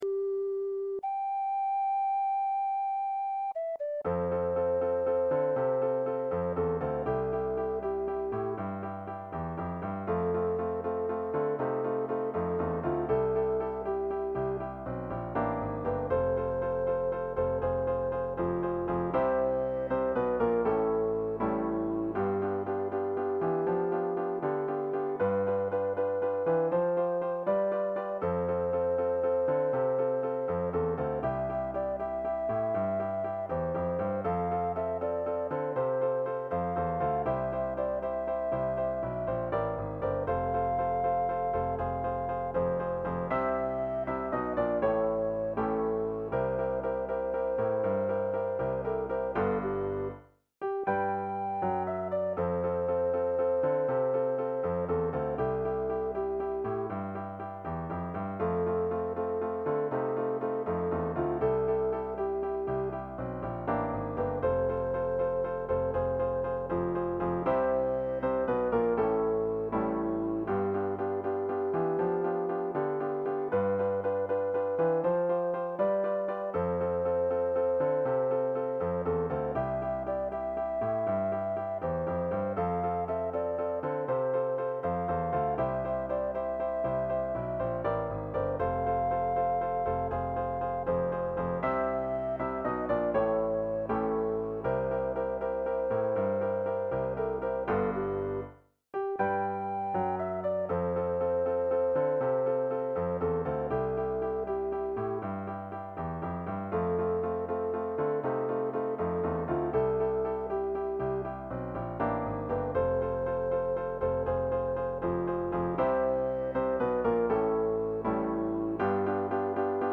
instrumental version of "Don't Hold The World On Your Shoulders."